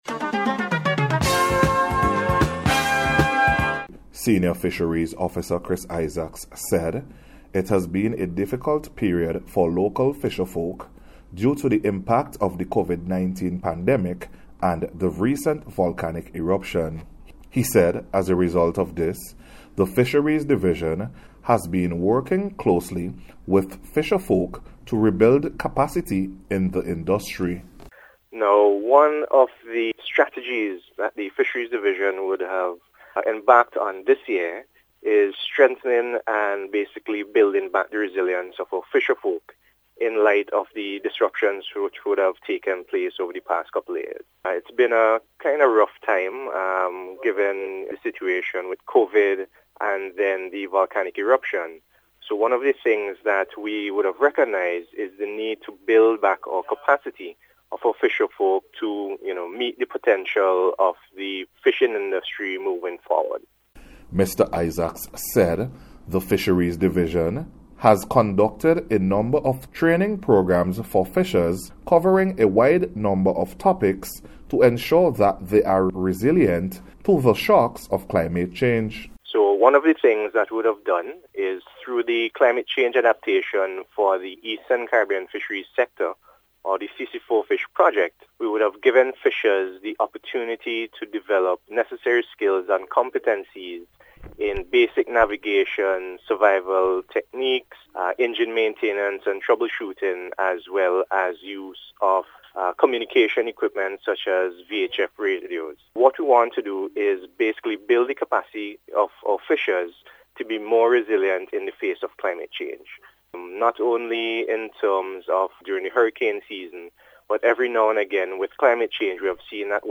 NBC’s Special Report for March 24th 2022